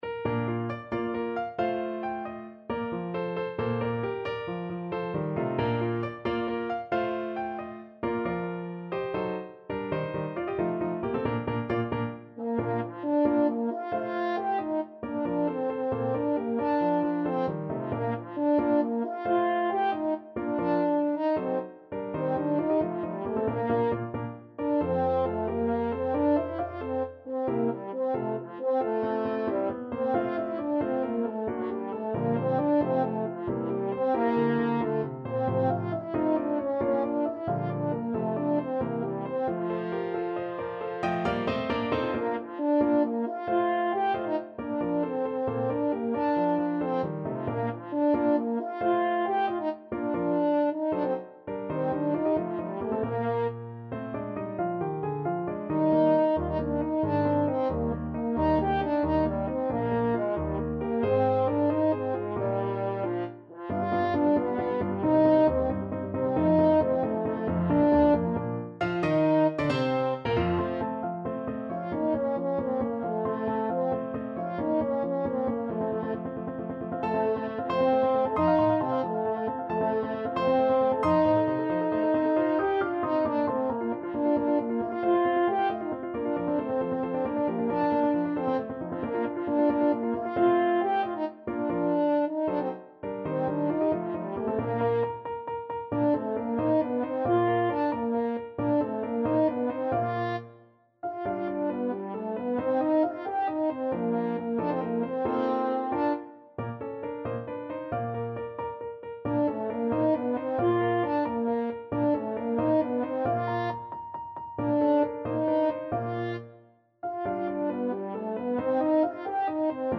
6/8 (View more 6/8 Music)
. = 90 Allegretto vivace
Classical (View more Classical French Horn Music)